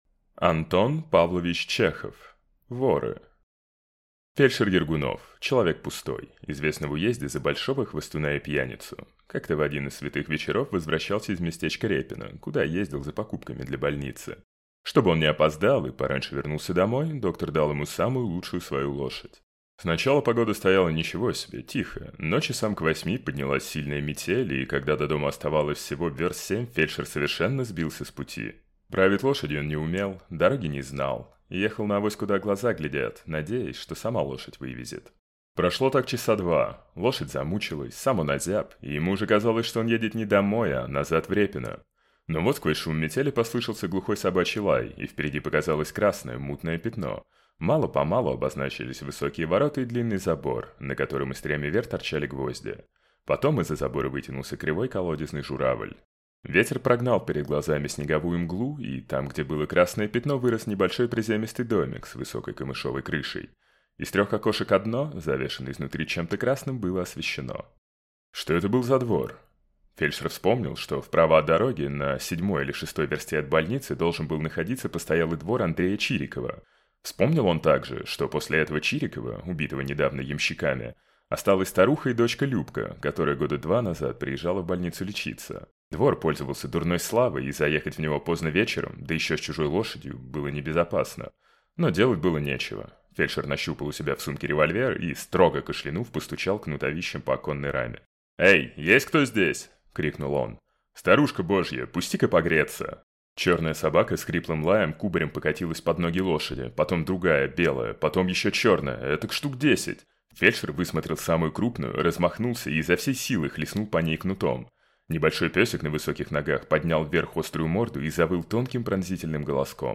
Аудиокнига Воры | Библиотека аудиокниг